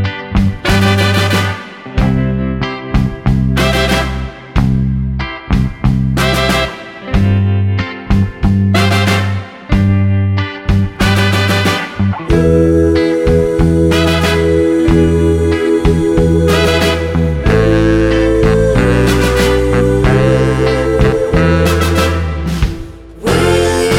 Pop (2000s)